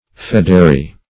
fedary - definition of fedary - synonyms, pronunciation, spelling from Free Dictionary Search Result for " fedary" : The Collaborative International Dictionary of English v.0.48: Fedary \Fed"a*ry\, n. A feodary.